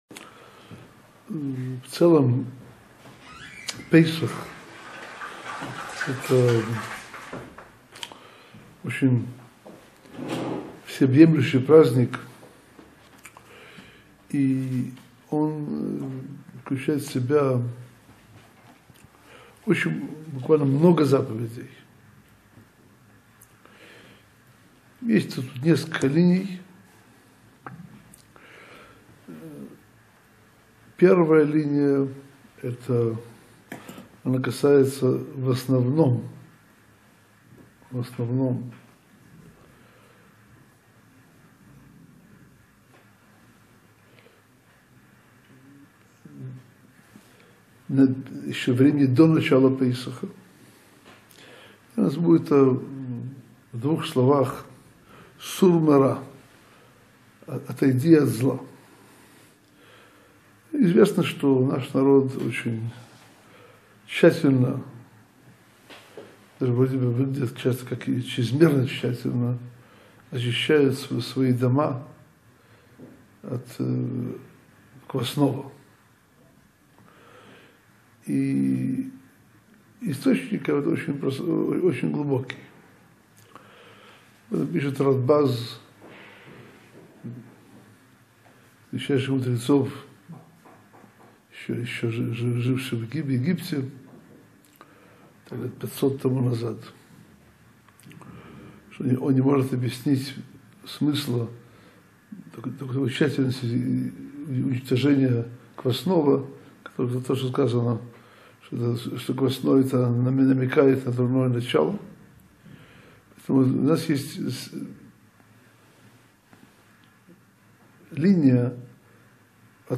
Структура Песаха и Седера - второй урок из серии коротких уроков на тему проведения пасхального седера и пасхальной Агады.